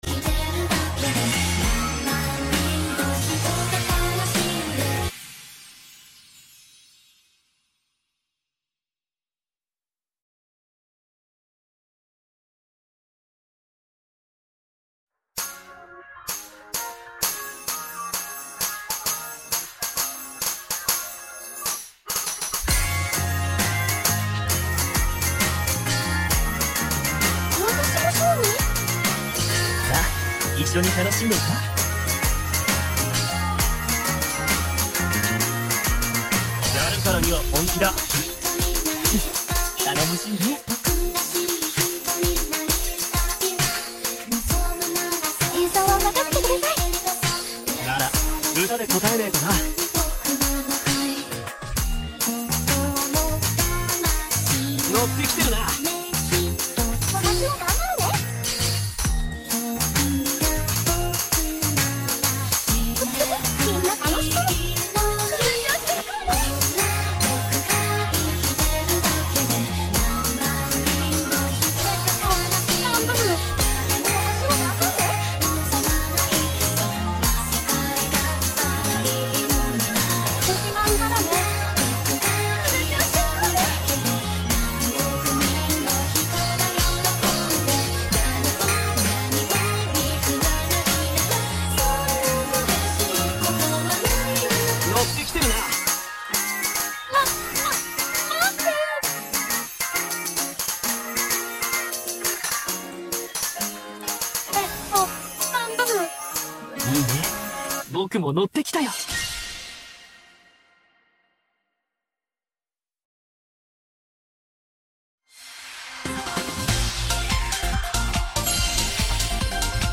sorry about the notification pop up towards the end